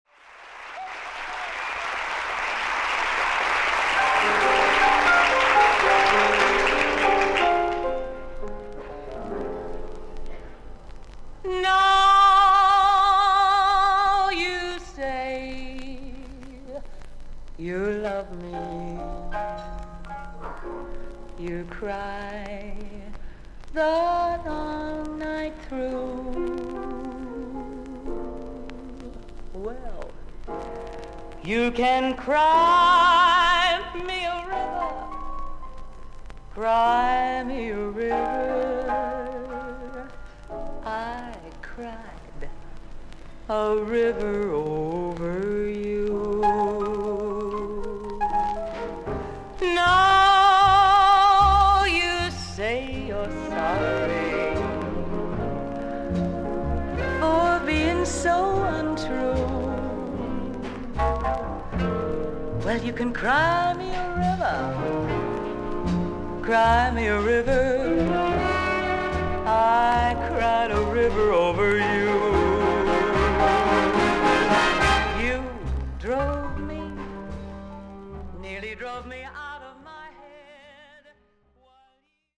全体的にサーフィス・ノイズあり。少々軽いパチノイズの箇所あり。
ウィスパー・ヴォイスで歌手としても人気を集めた女優。
オーケストラの伴奏でスタンダード・ナンバーを歌っています。